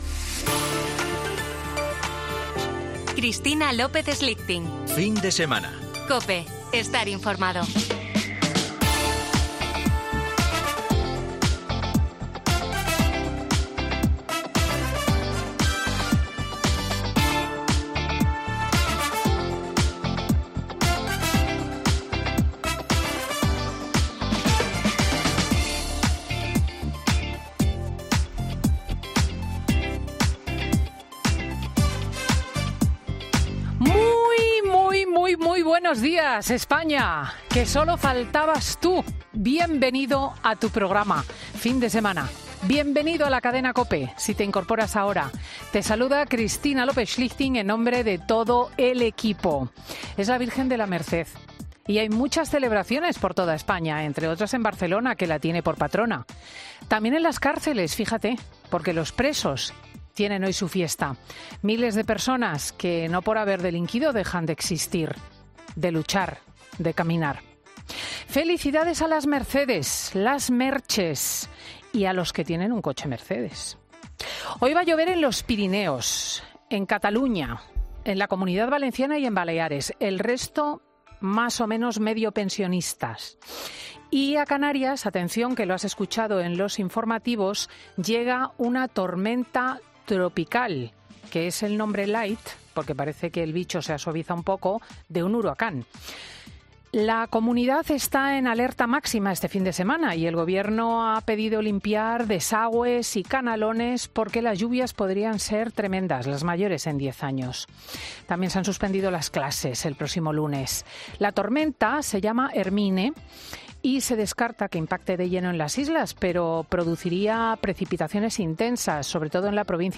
Escucha el monólogo de Cristina López Schlichting en Fin de Semana COPE
Monólogo de Cristina López Schlichting